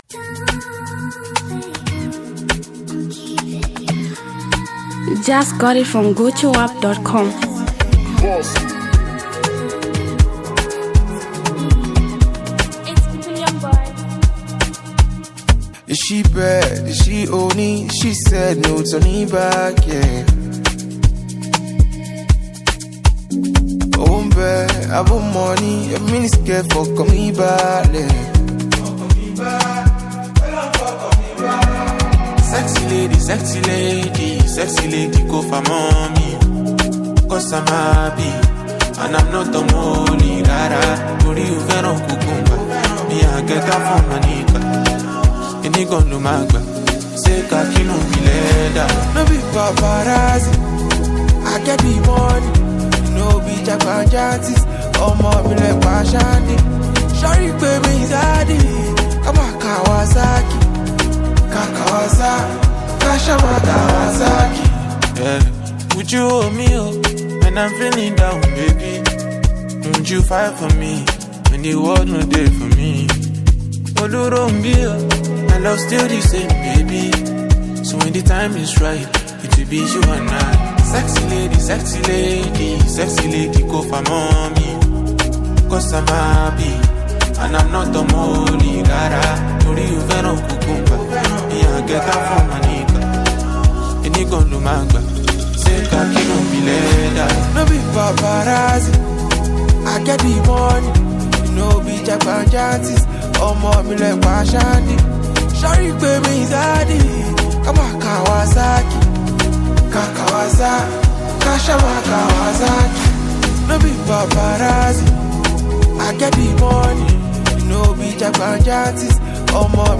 street anthem